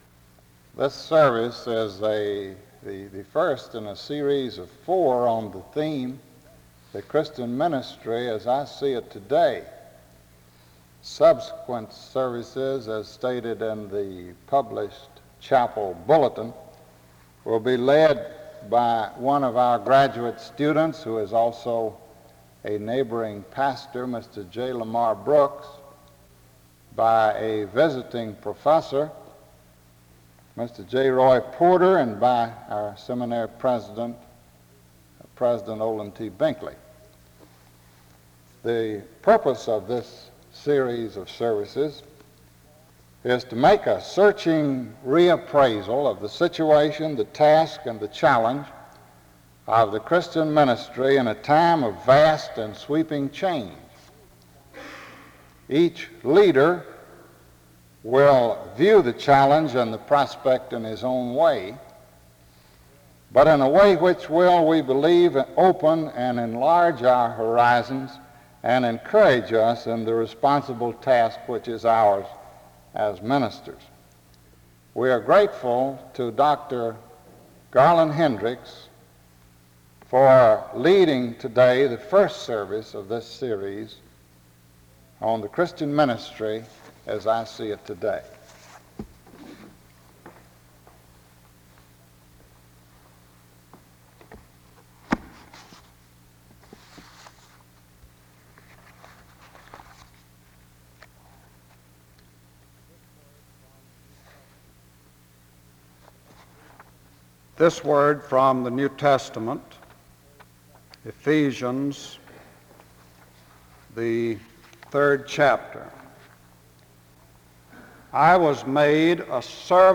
The service starts with an introduction to the speaker from 0:00-1:28.
SEBTS Chapel and Special Event Recordings SEBTS Chapel and Special Event Recordings